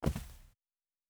Footstep Carpet Walking 1_08.wav